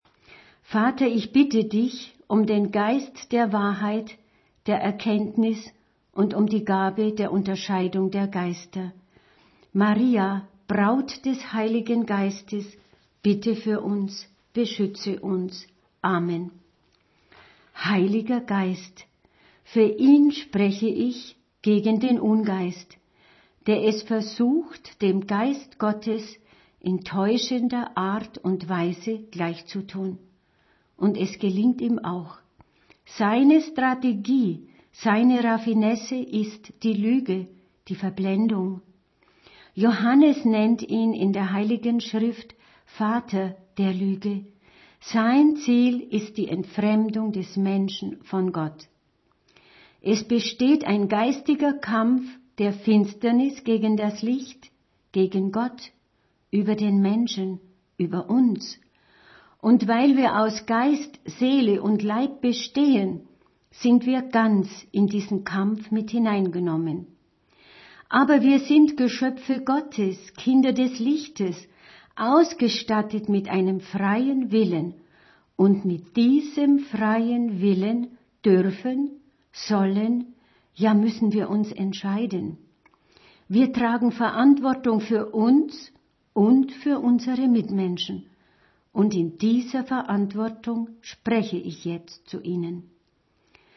Gebet 1:33 min - 277 KB